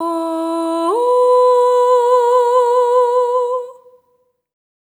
SOP5TH E4A-L.wav